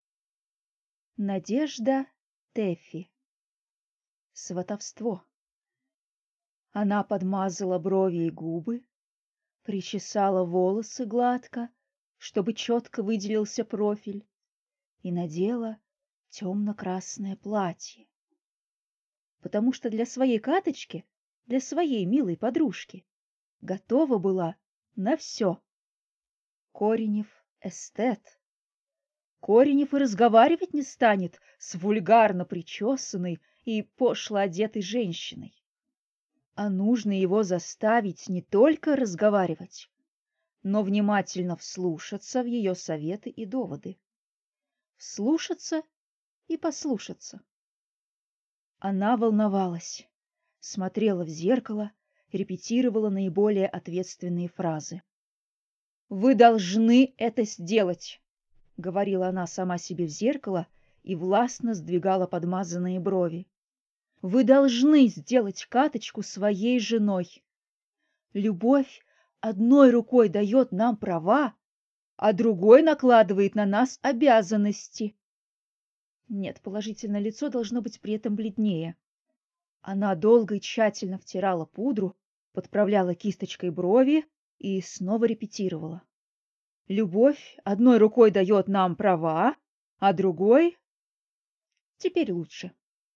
Аудиокнига Сватовство | Библиотека аудиокниг